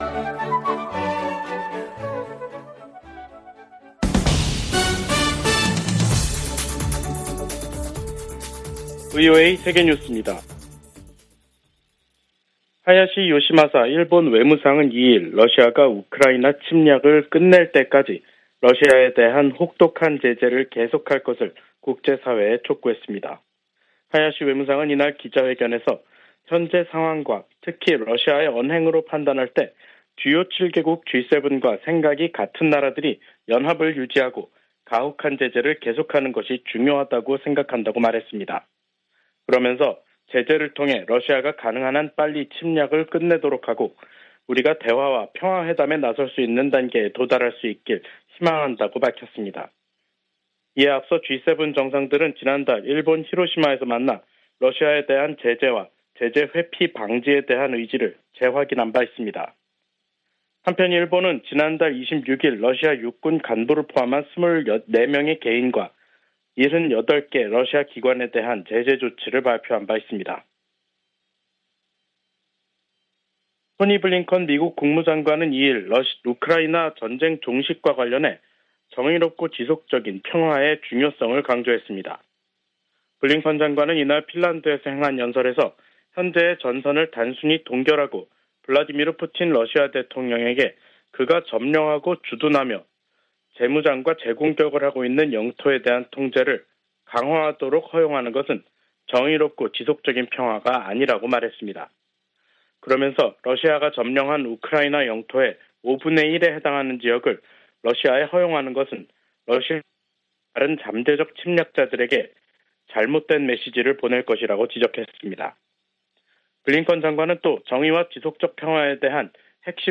VOA 한국어 간판 뉴스 프로그램 '뉴스 투데이', 2023년 6월 2일 3부 방송입니다. 유엔 안보리가 미국의 요청으로 북한의 위성 발사에 대한 대응 방안을 논의하는 공개 회의를 개최합니다. 미국과 한국 정부가 북한 해킹 조직 '김수키'의 위험성을 알리는 합동주의보를 발표했습니다.